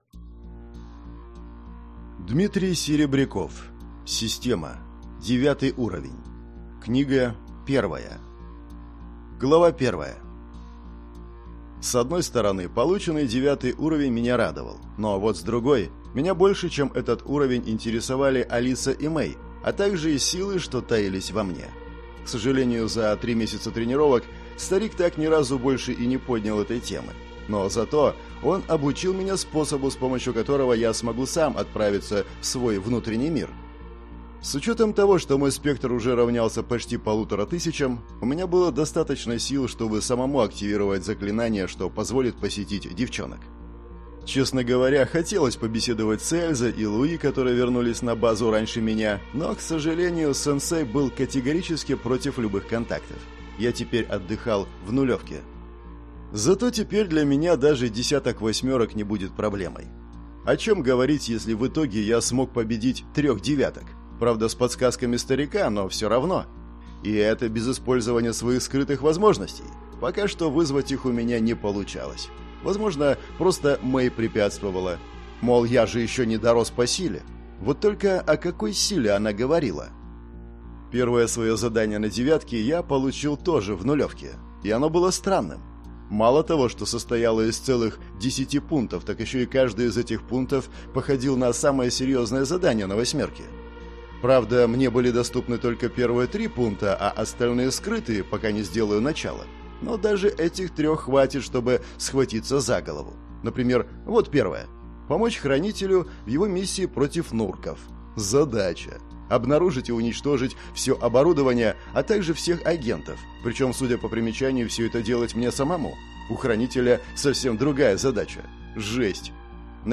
Аудиокнига Система. Девятый уровень. Книга 1 | Библиотека аудиокниг